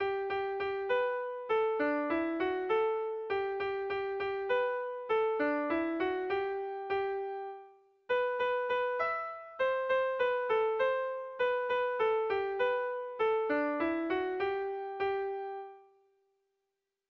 Dantzakoa
Lau puntuko berdina, 10 silabaz
A1A2BA2